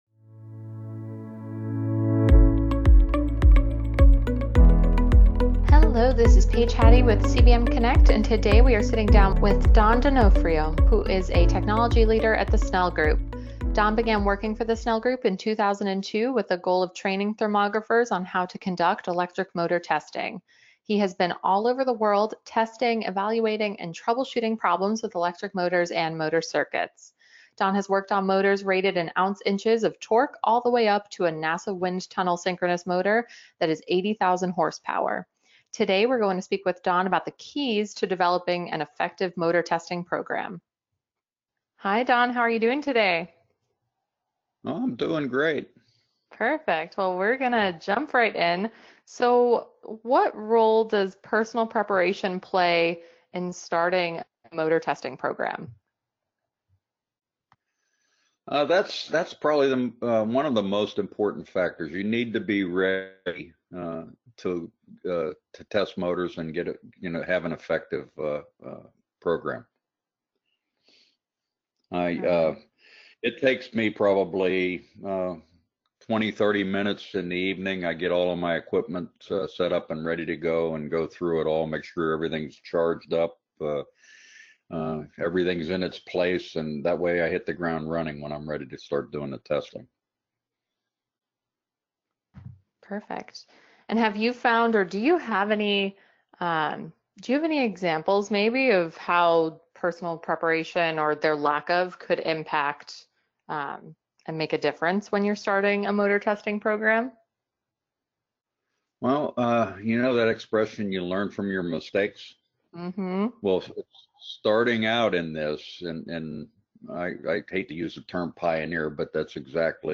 MOBIUS CONNECT Interviews The Keys to Developing an Effective EMT Jul 29 2019 | 00:15:07 1x 00:00 / 00:15:07 Subscribe Share Spotify RSS Feed Share Link Embed